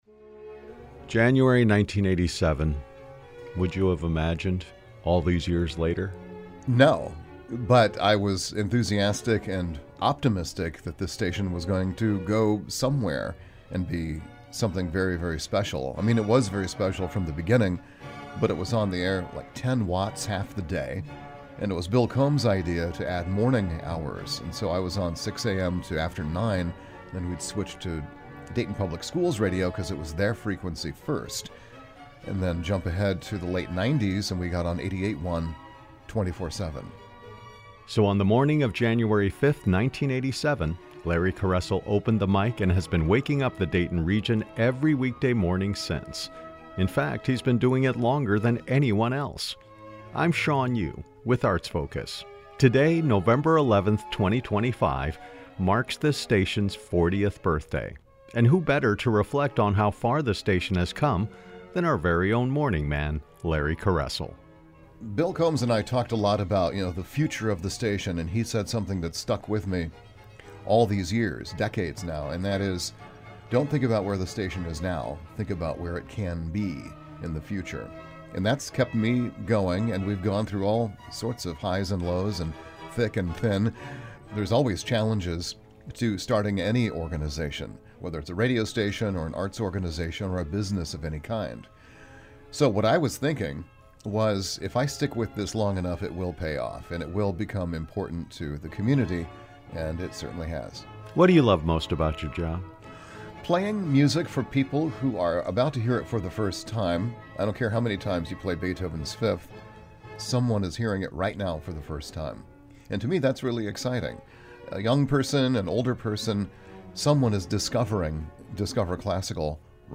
Each segment features a guest from the regional arts community discussing current activities, such as concerts, exhibitions and festivals.